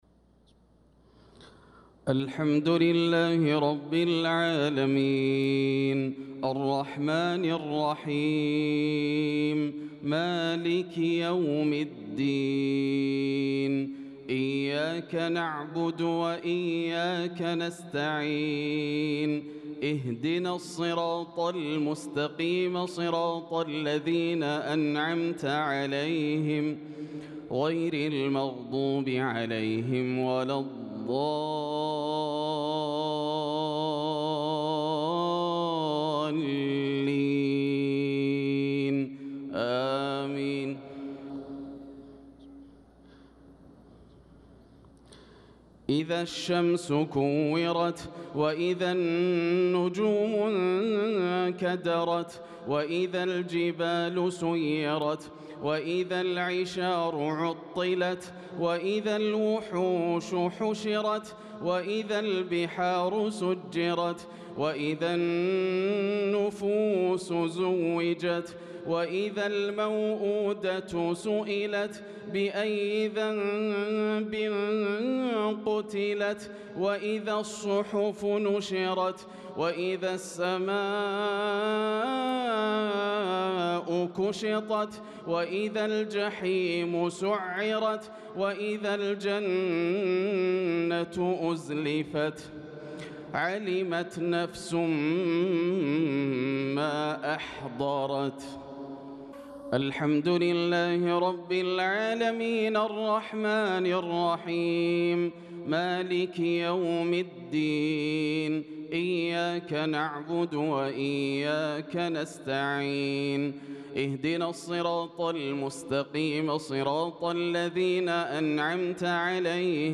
صلاة العشاء للقارئ ياسر الدوسري 28 ذو القعدة 1445 هـ
تِلَاوَات الْحَرَمَيْن .